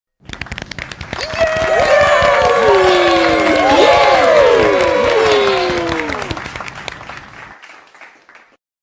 Download Cheer sound effect for free.
Cheer